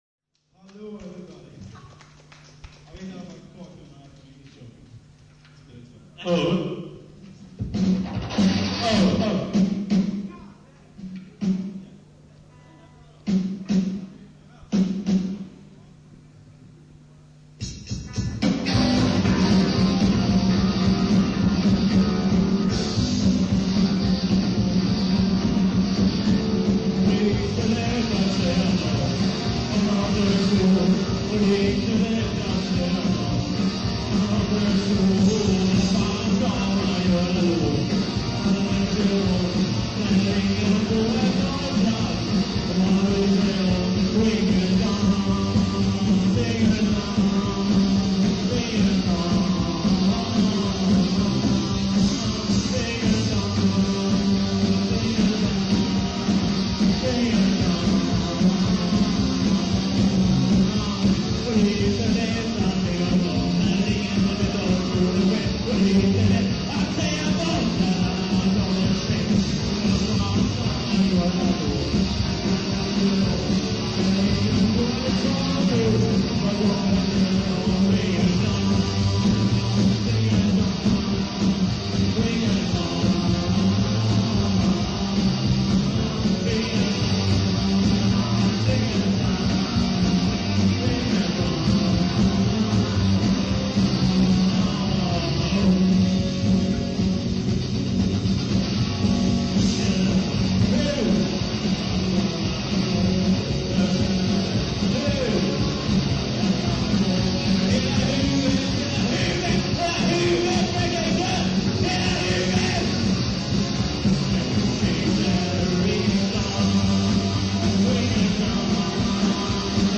Guitar
Drums
Bass
Voice